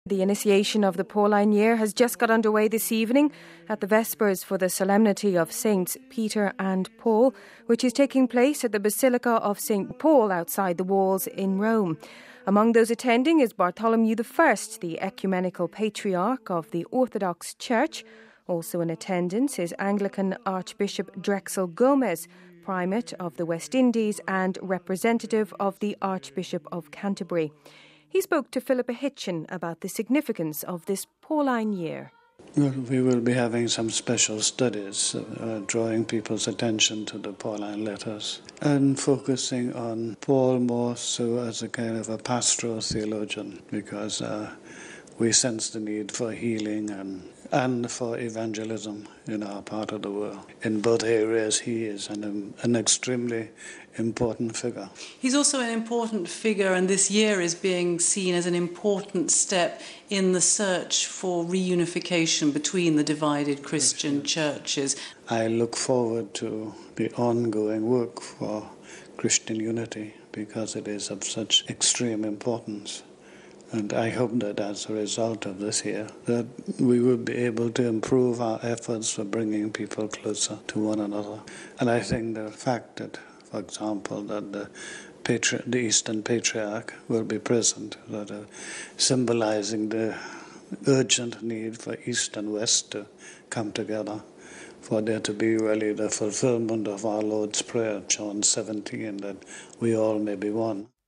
(28 June 08 - RV) The initiation of the Pauline year has just got underway at the Vespers for the solemnity of saints Peter and Paul, which is taking place at the Basilica of St Paul outside the Walls in Rome. Among those attending is Bartholomew the first, the ecumenical patriarch of the orthodox church.